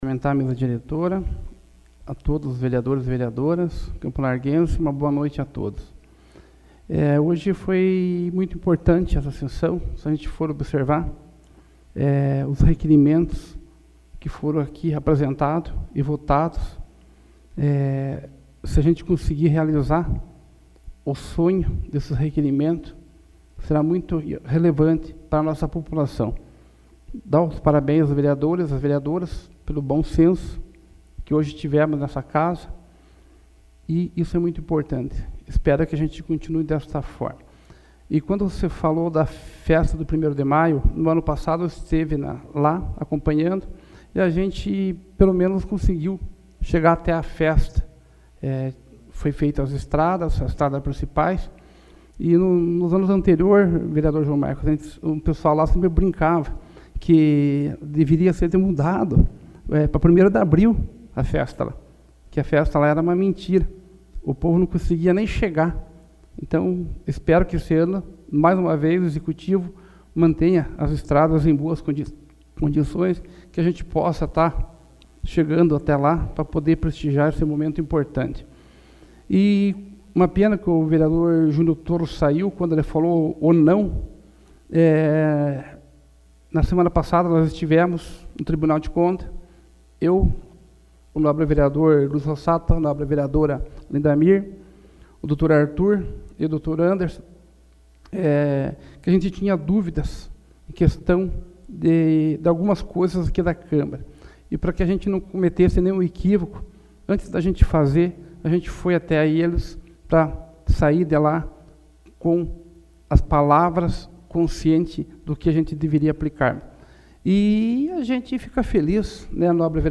SAPL - Câmara Municipal de Campo Largo - PR
Explicações pessoais AVULSO 08/04/2014 Dirceu Mocelin